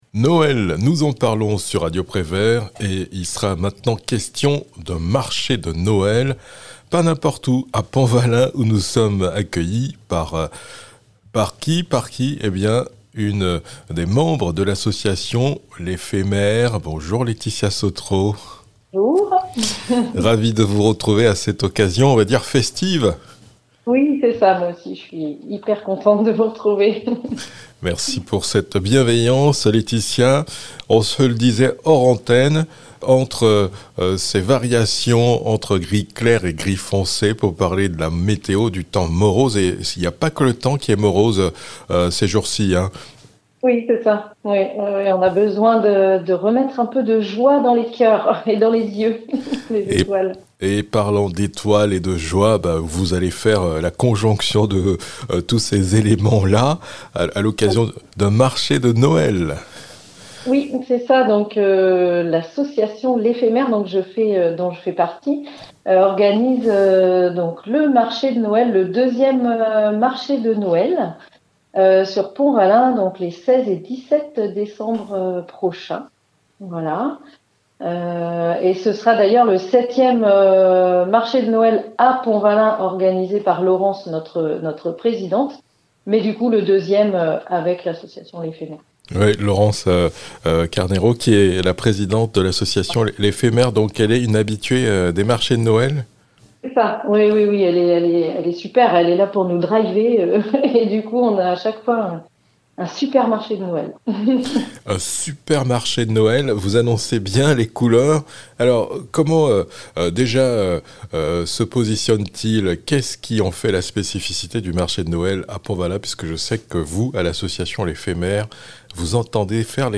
Et petit cadeau surprise pour les auditeurs, elle donne de la voix pour Noël :)